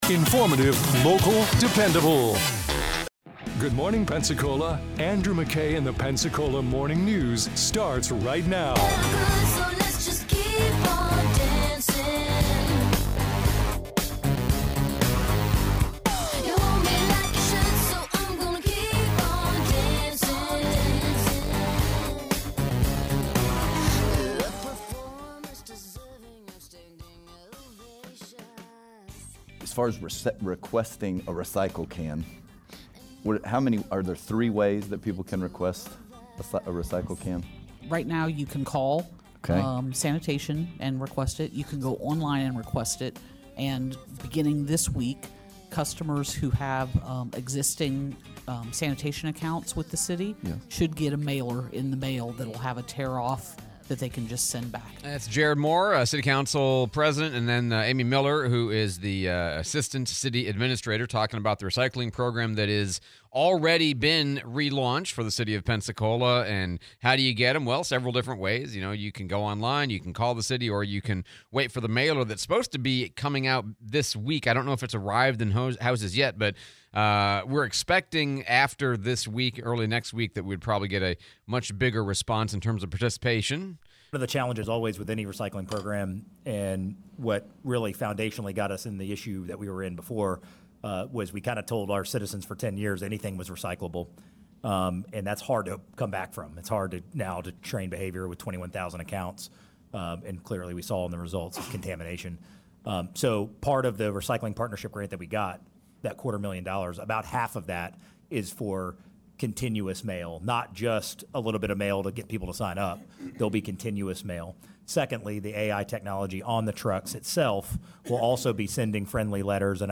City of Pensacola recycling and interview with PPD Chief Kristin Brown